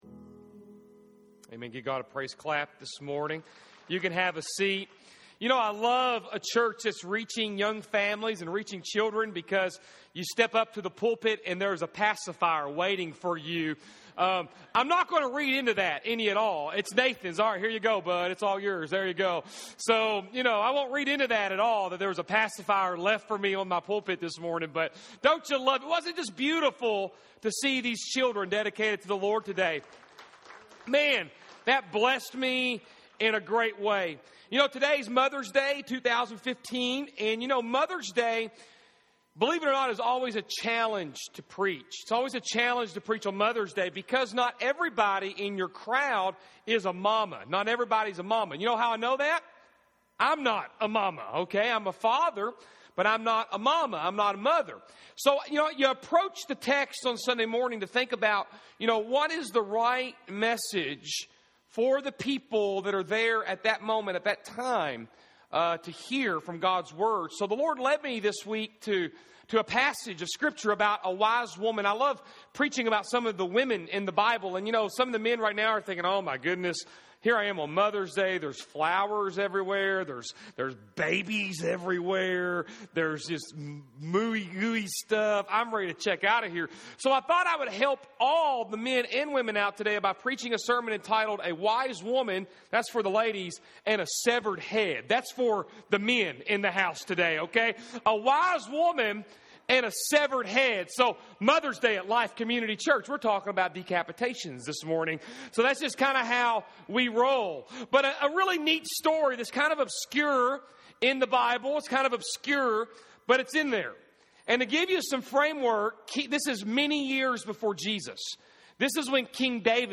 May 10, 2015 A Wise Woman and A Severed Head Service Type: Sunday AM Mothers Day 2015.